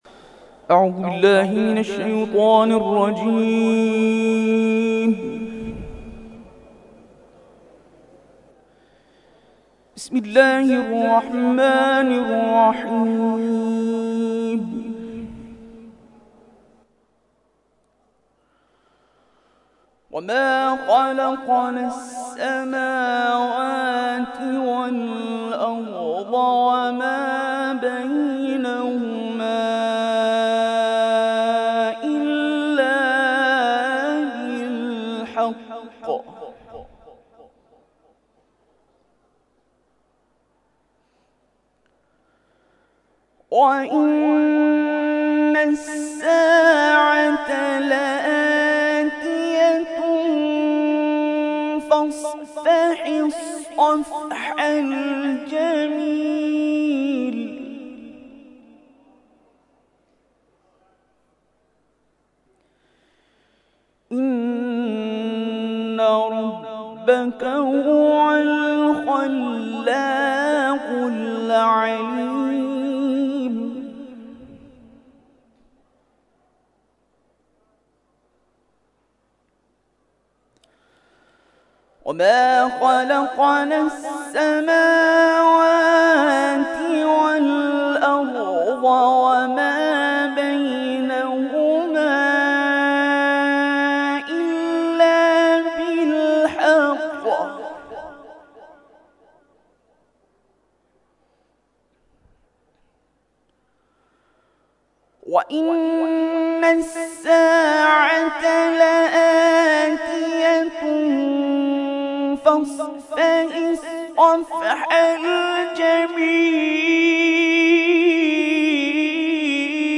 نماز جمعه